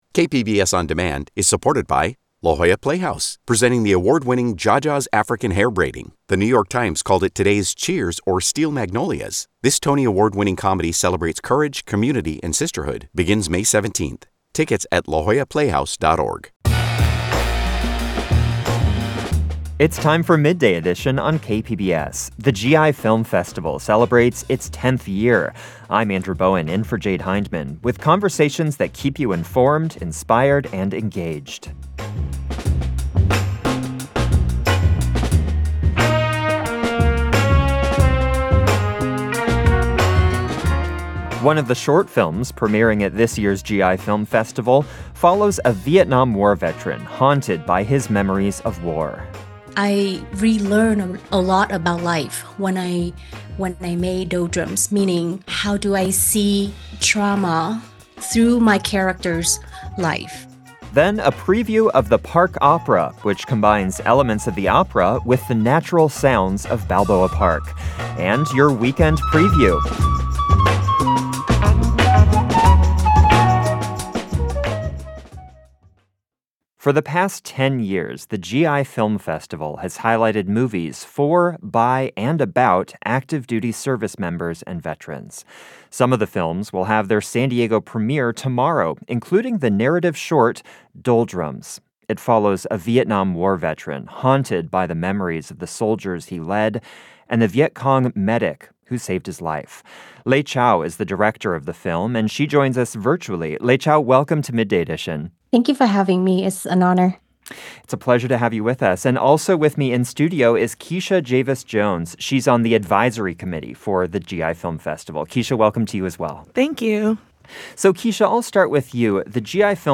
Midday Edition uplifts voices in San Diego with fearless conversations about intriguing issues.
Guests share diverse perspectives from their expertise and lived experience.